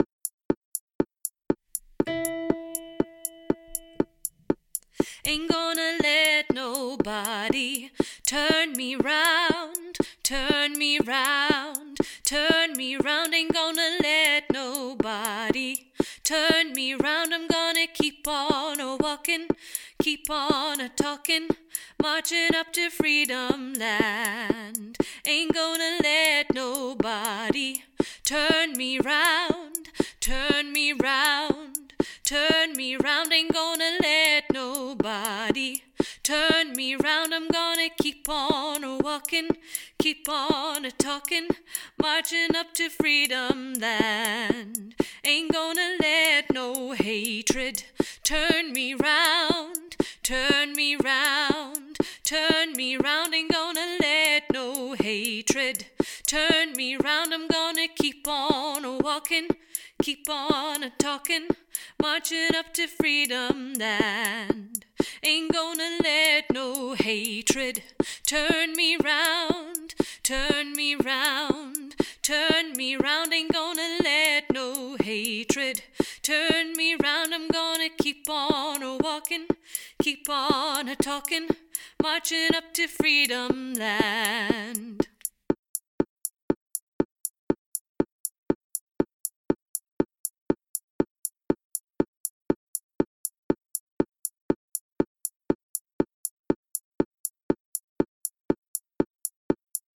AGLN Tenor